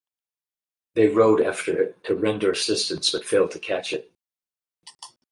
Pronounced as (IPA) /ɹəʊd/